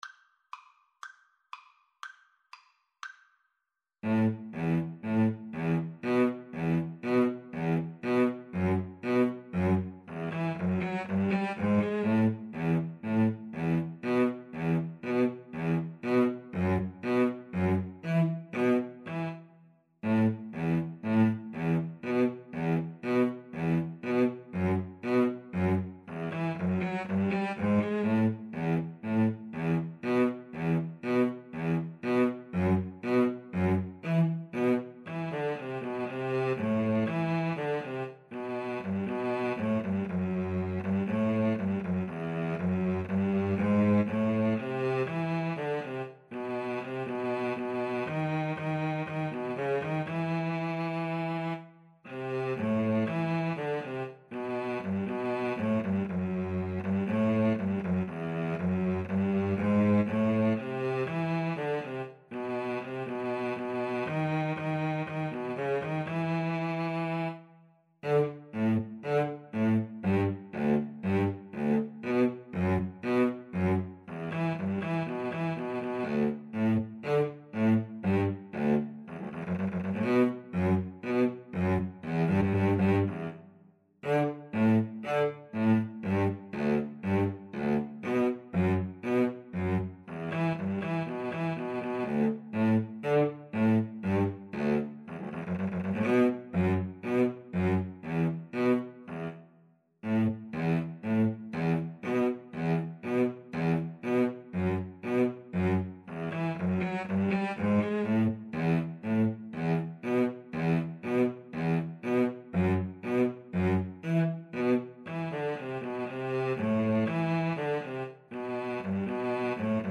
Cello 1Cello 2
E minor (Sounding Pitch) (View more E minor Music for Cello Duet )
Fast Two in a Bar =c.120